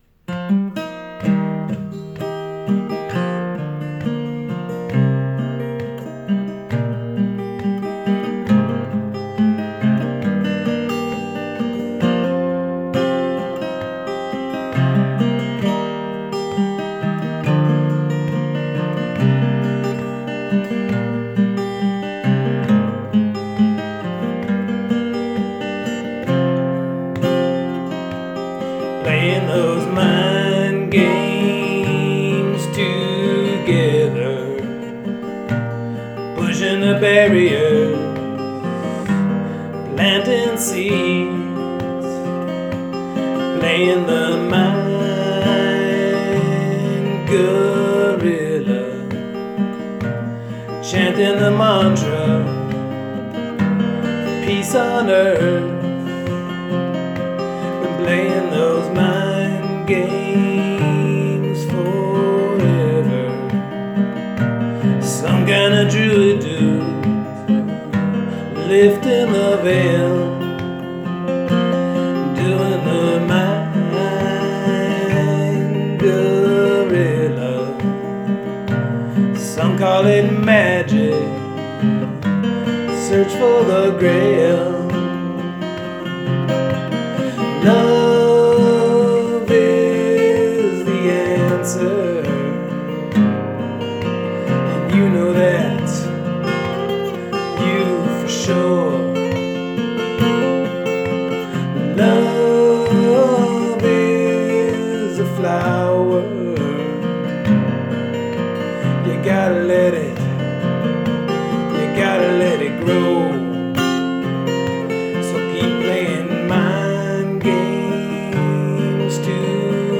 on my guitar for many years
on my phone with my travel-sized Martin guitar.